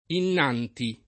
innanzi [ inn # n Z i ] (antiq. inanzi [ in # n Z i ]) avv. e prep.